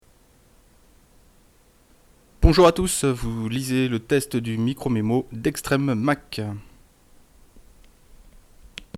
– Excellente qualité sonore (en entrée et sortie)
Le micro et sa bonnette offrent un très bon rendu, comme vous pouvez l’écouter par ici : 007.jpg.jpg Le MicroMemo propose enfin, en retirant le micro, une entrée-son, offrant ainsi une fonctionnalité supplémentaire au baladeur : on pourra en effet y brancher n’importe quel engin disposant d’un port jack, relier les deux et choper ainsi sur l’iPod le flux sortant de l’appareil.